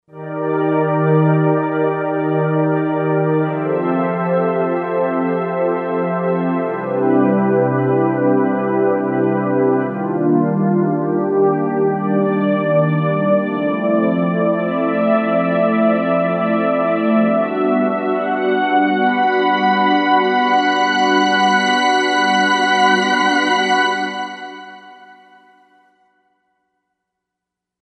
Synthesizer module
Korg M1Rex factory patches mp3 audio demos
37 Analogpad2
37 Analogpad2.mp3